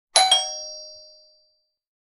Ring Doorbell Sound Effect Free Download
Ring Doorbell